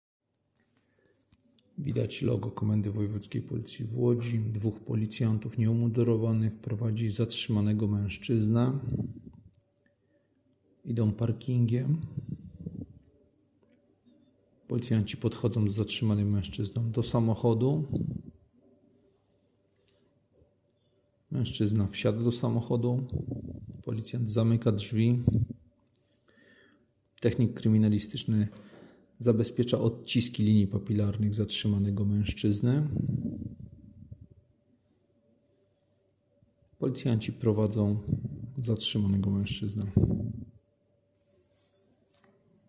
Nagranie audio Audiodeskrypcja do filmu